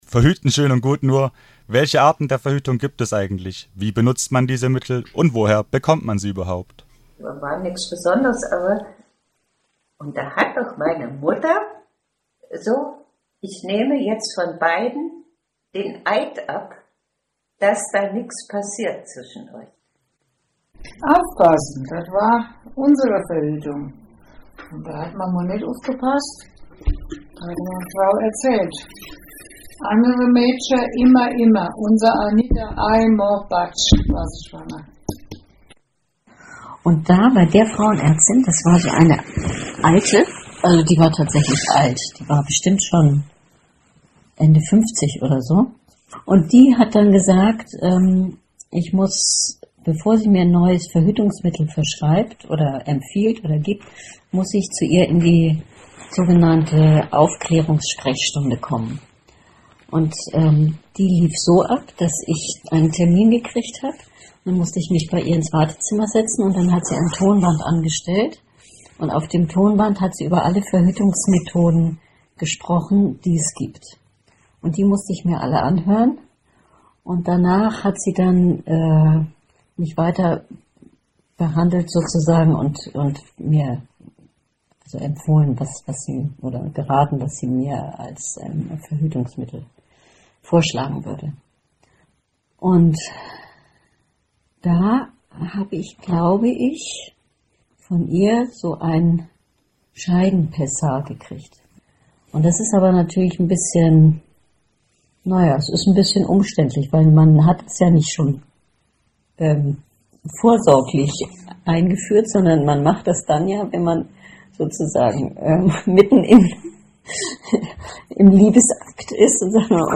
Diesen Fragen widmen sich die Interviews dreier Frauen der Jahrgänge 1935, 1943 und 1955: So werden beispielweise Frauenarzt/-ärztinnenbesuche, Erfahrungen mit Abtreibung, Auseinandersetzungen bezüglich Sexualmoral, aber auch gesellschaftliche Umbrüche in ihren Erzählungen geschildert.
Die Interviewausschnitte sind absteigend nach Alter zusammengeschnitten.